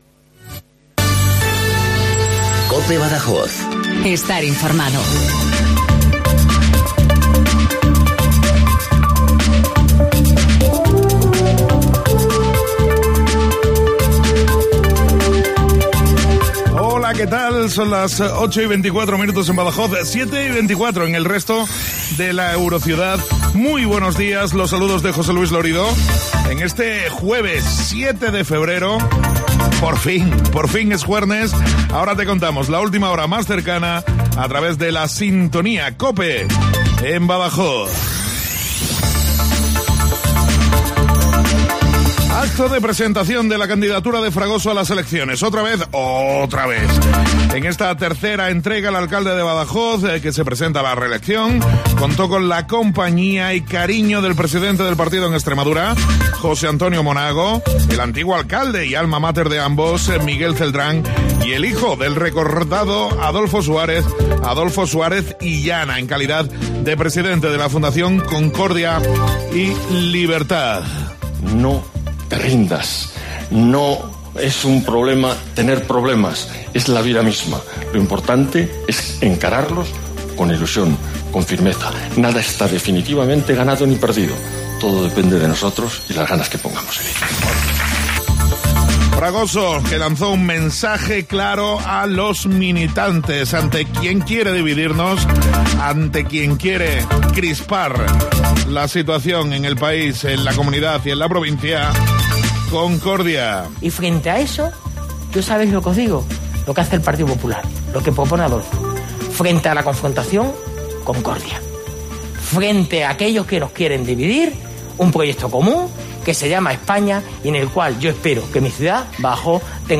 INFORMATIVO LOCAL BADAJOZ 0824